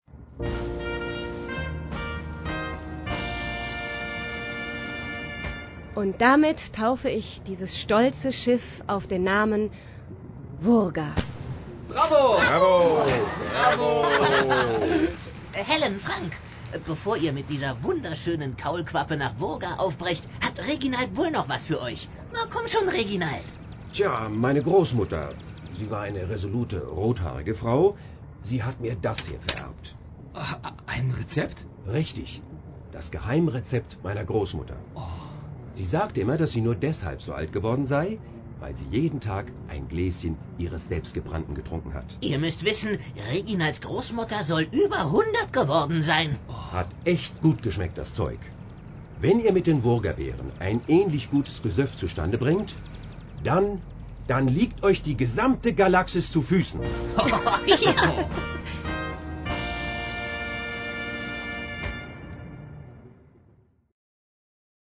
Das zweite PERRY RHODAN-Hörbuch von Eins A Medien bietet eigentlich keine Hörbuchfassung in eigentlichen Sinn, sondern eine sehr gelungene Hörspielbearbeitung des Romans von Hubert Haensel.
Den Charaktersprechern wird hier mehr Raum gegeben, was das Hörbuch sehr interessant macht.